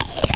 app_bite.wav